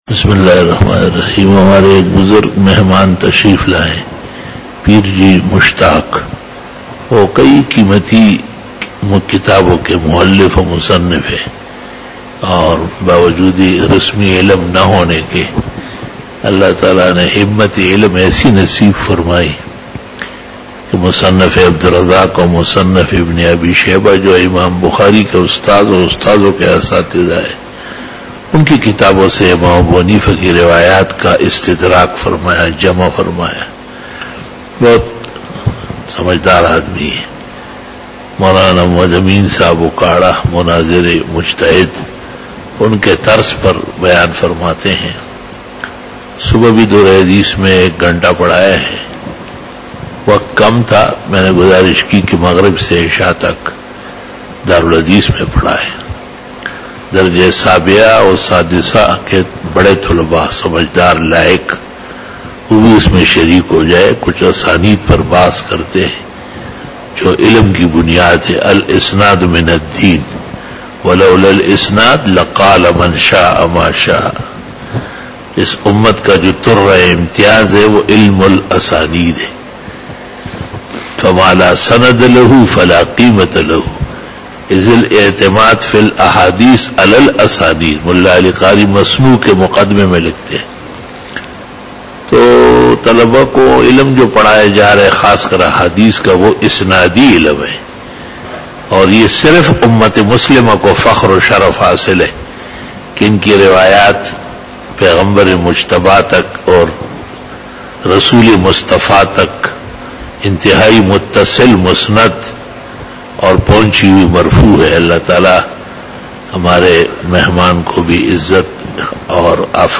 After Namaz Bayan
After Fajar Byan
بیان بعد نماز فجر